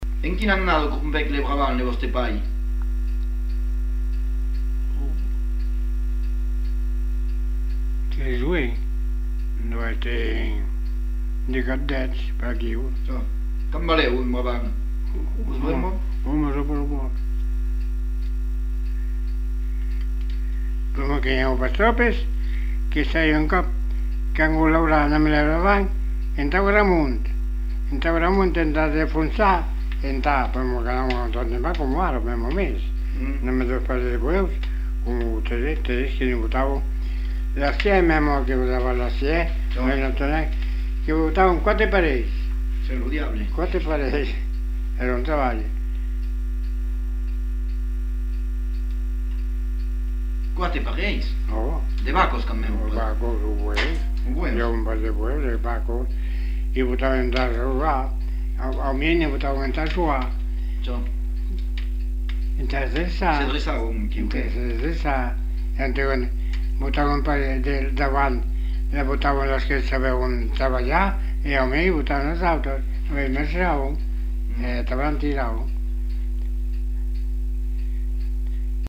(enquêteur)
Lieu : Espaon
Genre : témoignage thématique